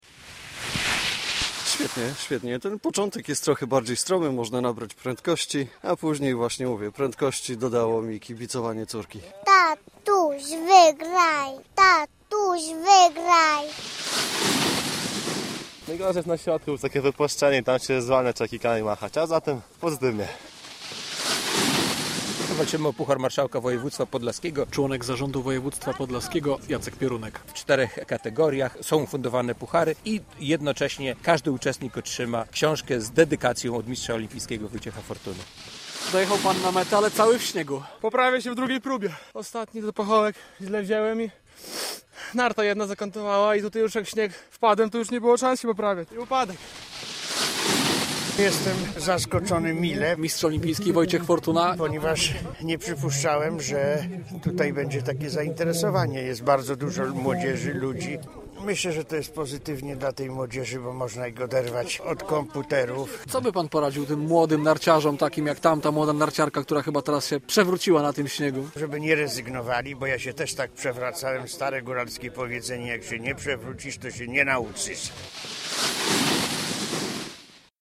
Narciarski slalom gigant w Rybnie - relacja